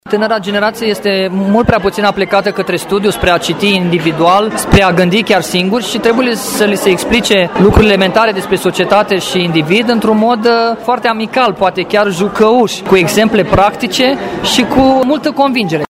Cursul, intitulat ,,Metode și tehnici de predare a educației juridice nonformale în școli și licee”, a fost susținut de judecătorul Cristi Dănileț și s-a adresat studenților Facultății de Drept din Brașov, care au umplut amfiteatrul. Au fost patru ore interactive, în care judecătorul Cristi Dănileț a oferit un exemplu de comunicare nonconformistă, puțin obișnuită chiar și pe băncile facultății: